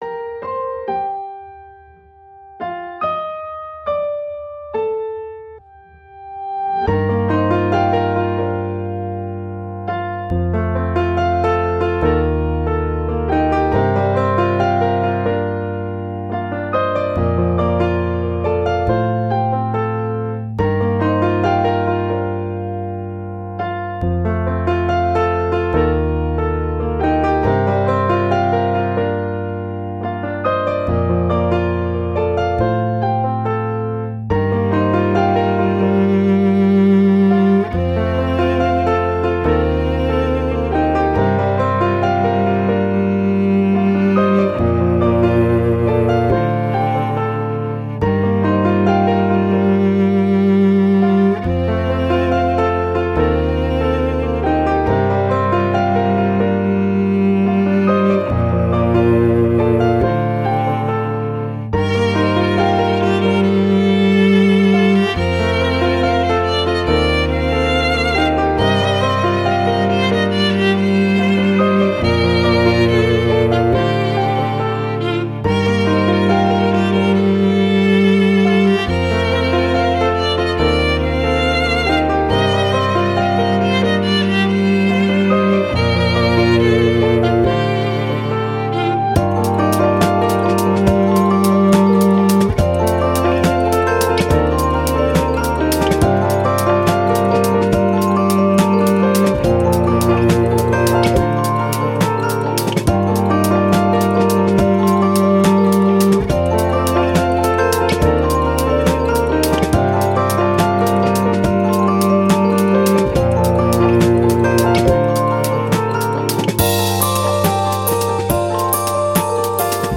Soundtrack, Downtempo, Strings, Piano, Story, Journey